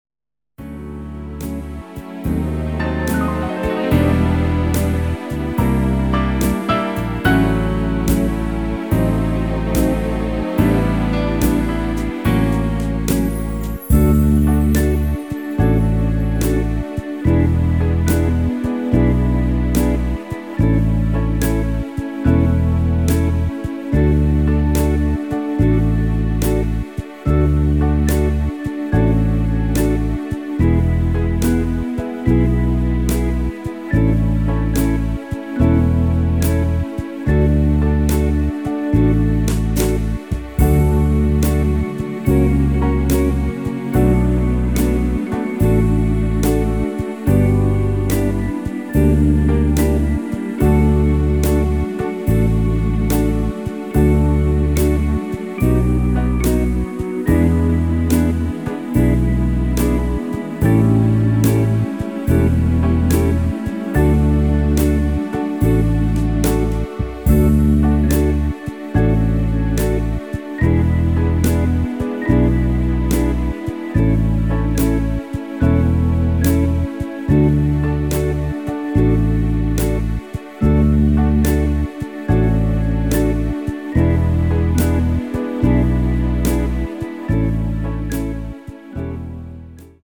• Tonart: D Dur (weitere auf Anfrage)
• Art: Dinner Version
• Das Instrumental beinhaltet NICHT die Leadstimme
Klavier / Streicher